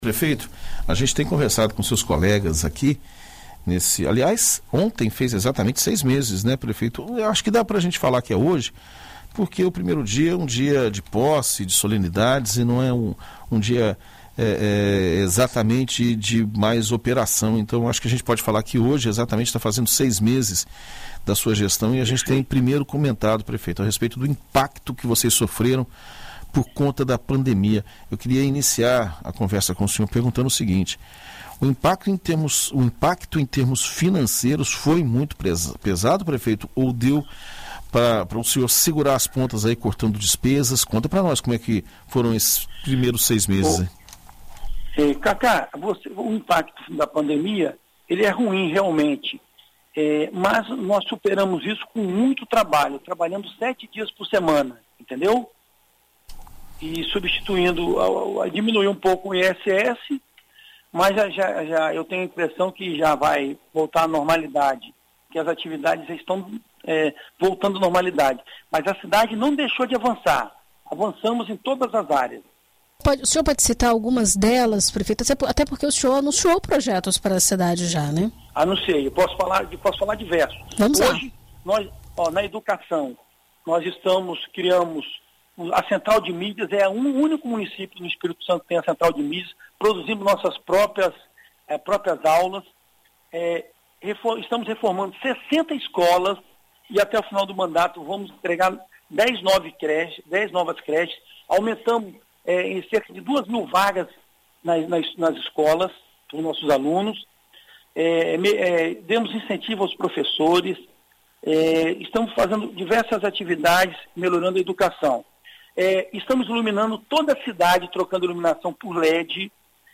Em entrevista à BandNews FM Espírito Santo nesta sexta-feira (02), o prefeito Euclério Sampaio conversa sobre as principais propostas dos primeiros seis meses de mandato e aproveita para detalhar o projeto, que deve trazer impactos também para a área de saneamento básico.